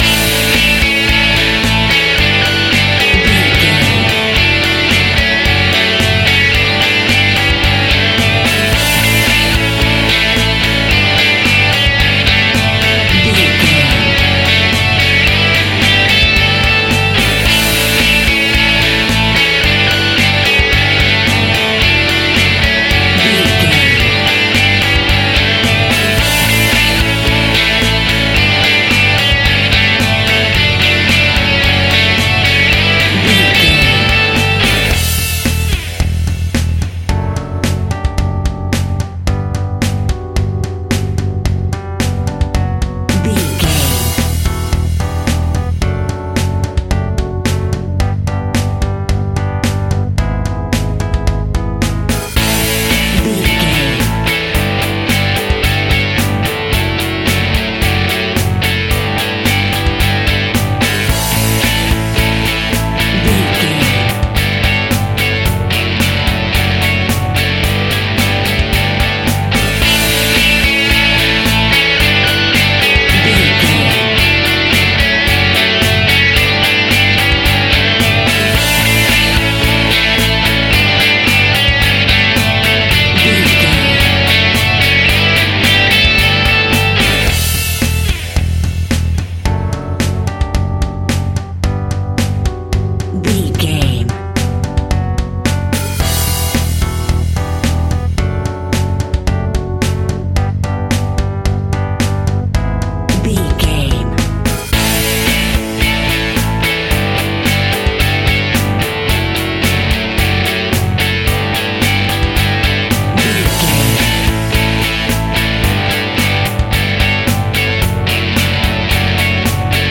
Fast paced
Mixolydian
indie rock
energetic
uplifting
Instrumental rock
distortion
drums
bass guitar
electric guitar